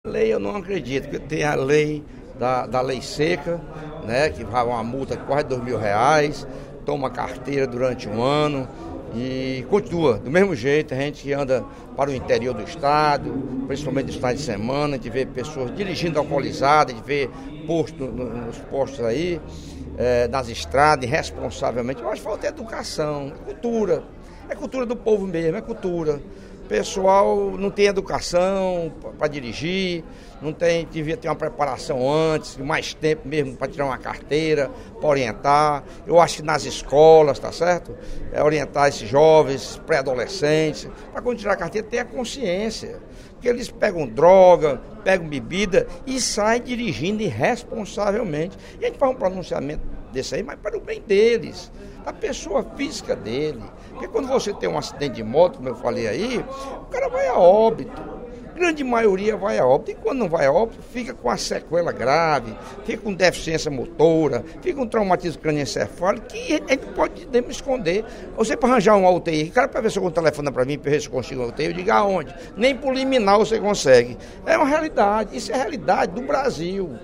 Durante o primeiro expediente da sessão plenária desta quinta-feira (13/03), o deputado Lucílvio Girão (SDD) alertou para o aumento de acidentes nas estradas cearenses, especialmente de motociclistas.